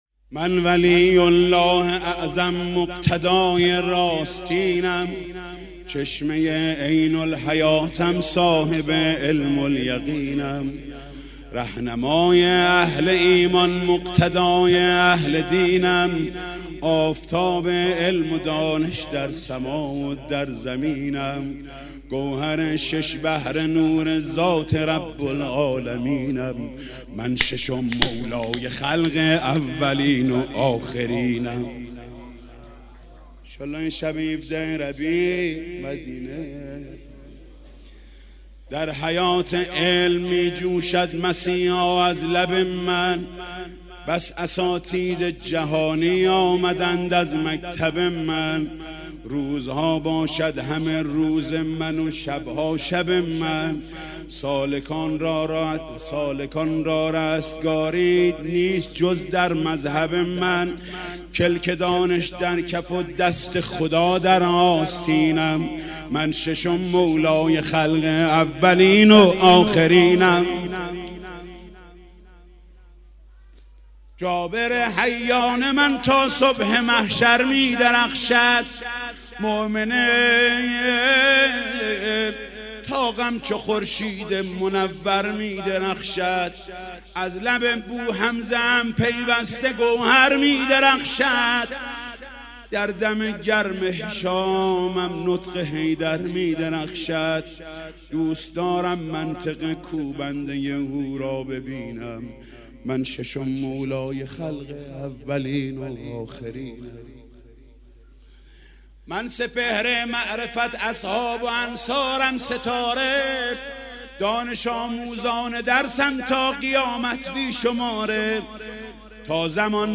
خیمه گاه - عاشقان اهل بیت - مدح- من ولی الله اعظم مقتدای راستینم- حاج محمود کریمی